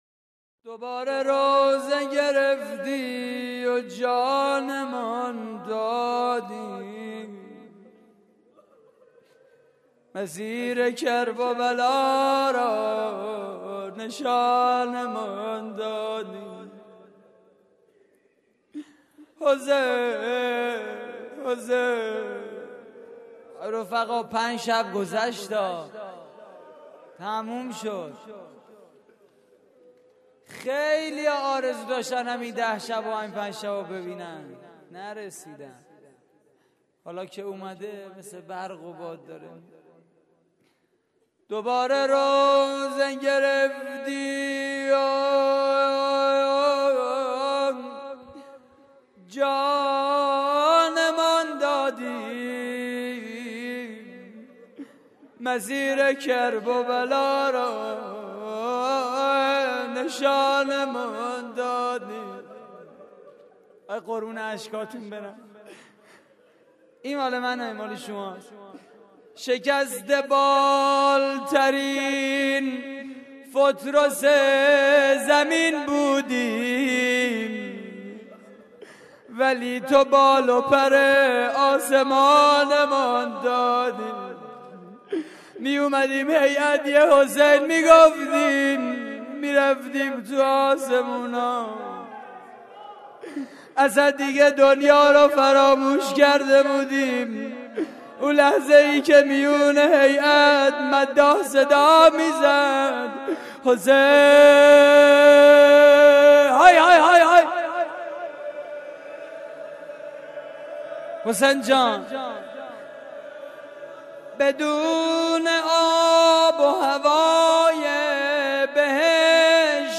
روضه پایانی: مسیر کرببلا را نشانمان دادی
مراسم عزاداری شب پنجم ماه محرم / هیئت الزهرا (س) – دانشگاه صنعتی شریف